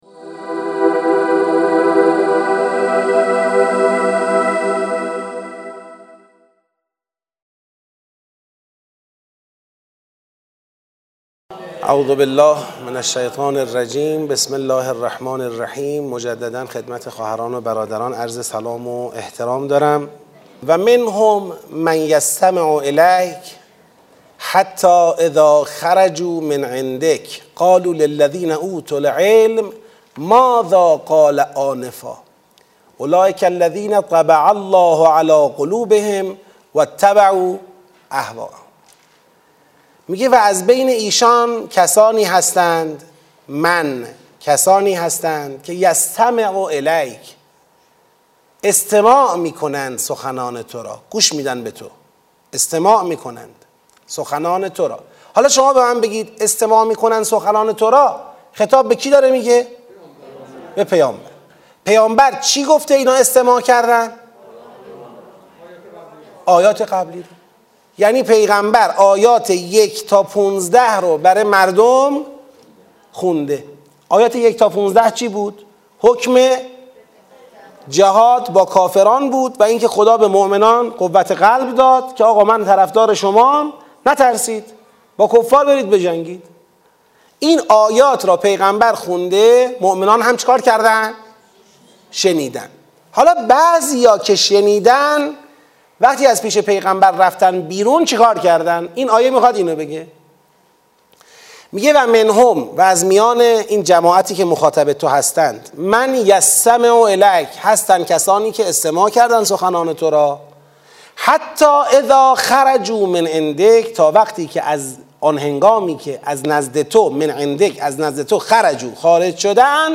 ترم ششم این دوره از مهر ۱۴۰۱ در سالن شهید آوینی مسجد حضرت ولیعصر (عج) شهرک شهید محلاتی آغاز شد و طی ۱۲ هفته برگزار خواهد شد.